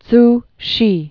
(ts shē)